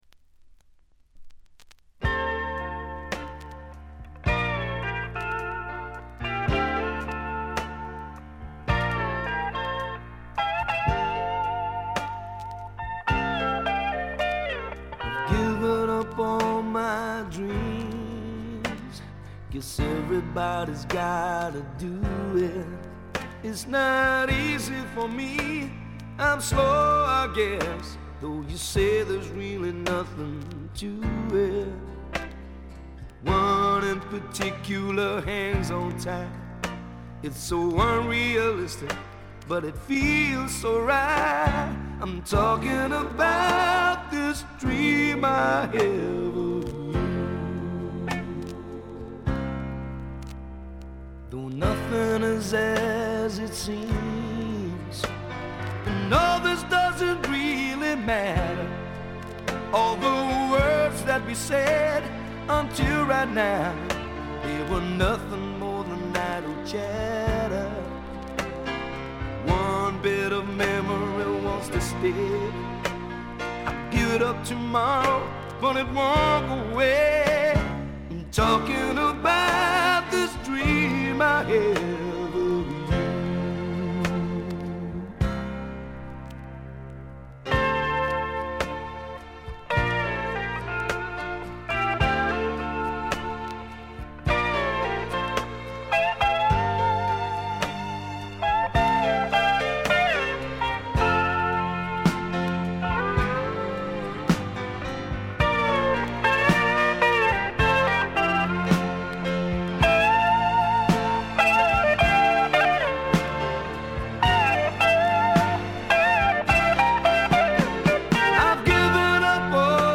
静音部で軽微なチリプチ。散発的なプツ音少し。
思い切り泥臭さいサウンドなのにポップでキャッチーというのも素晴らしい。
試聴曲は現品からの取り込み音源です。